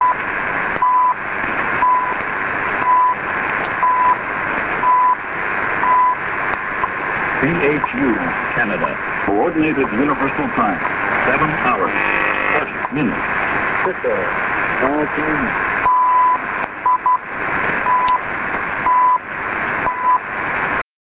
CHU/Canada, Time Signal on 7335 kHz (2001)#